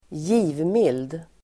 Ladda ner uttalet
givmild adjektiv, generous Uttal: [²j'i:vmil:d] Böjningar: givmilt, givmilda Synonymer: generositet, generös Definition: som gärna ger, frikostig (happy to give, liberal) Exempel: en givmild natur (a generous nature), med givmild hand (generously)